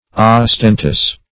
Ostentous \Os*ten"tous\, a.